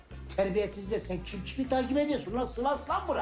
takip-alert-sound.mp3